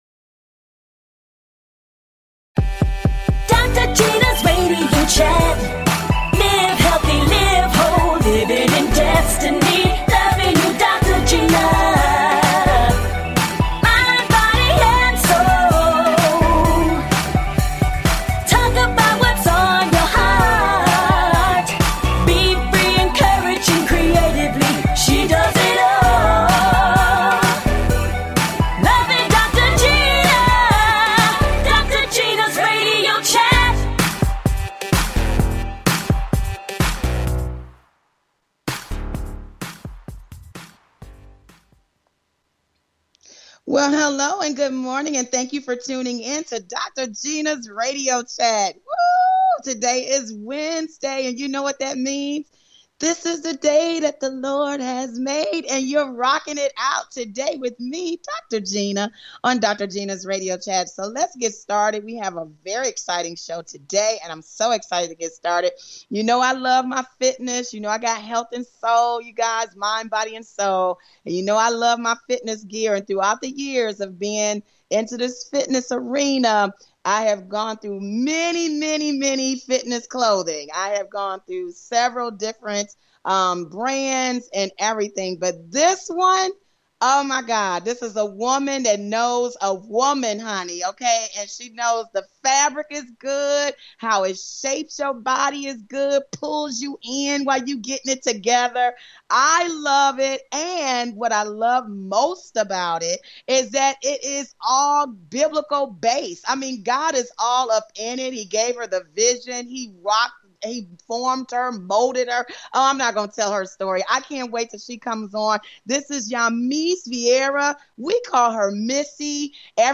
A talk show of encouragement.